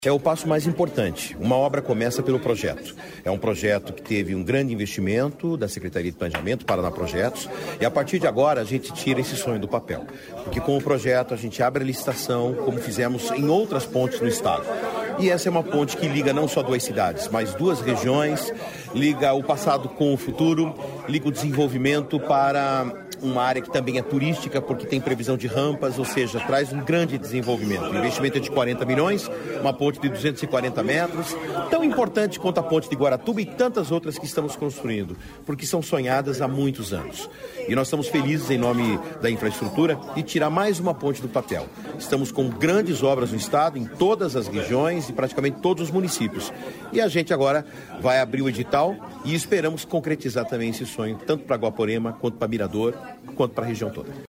Sonora do secretário de Infraestrutura e Logística, Sandro Alex, sobre nova ponte no Rio Ivaí